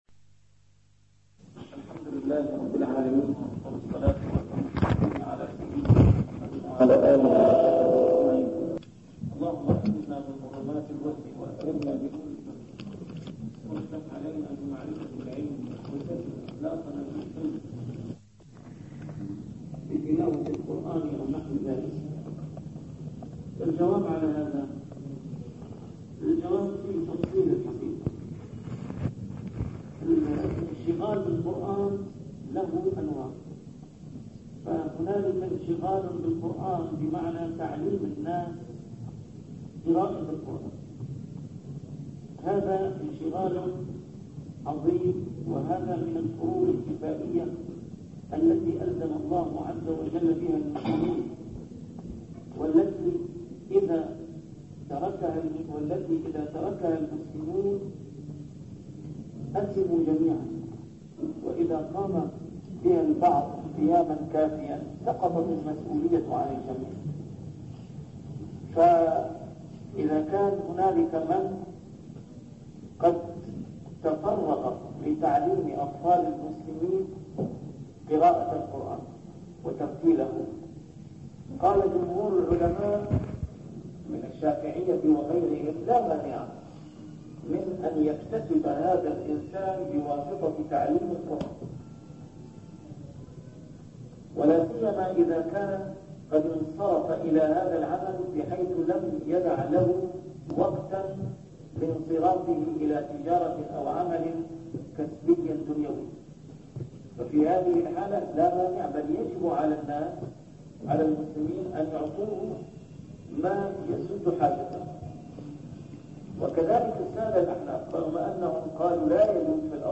A MARTYR SCHOLAR: IMAM MUHAMMAD SAEED RAMADAN AL-BOUTI - الدروس العلمية - شرح الأحاديث الأربعين النووية - بداية شرح الحديث الثامن: حديث ابن عمر (أمرت أن أقاتل الناس حتى يشهدوا) 37